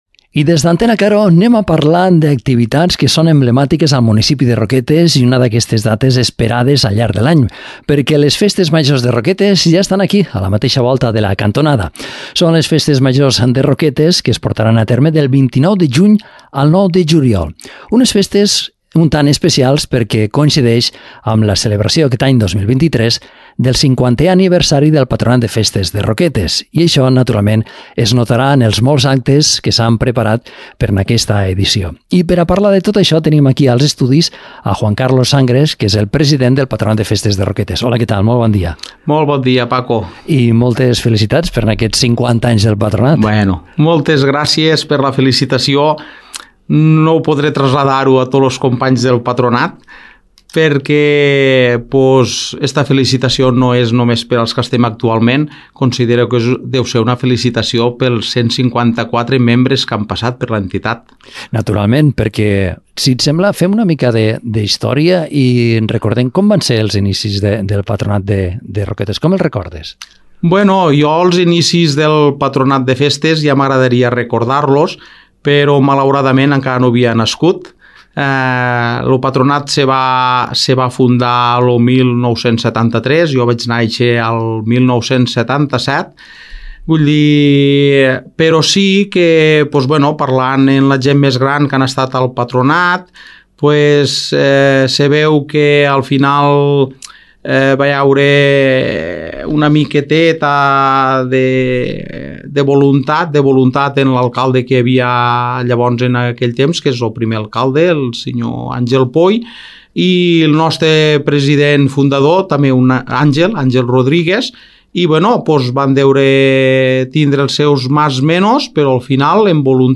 50è aniversari del Patronat de Festes de Roquetes, entrevista